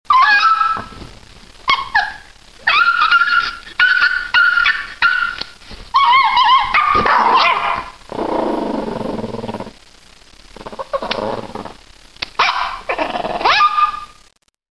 Halverwege bemoeit Poupoune zich er ook mee, en blaft dat ie zich niet zo moet aanstellen.
cutting_nails.mp3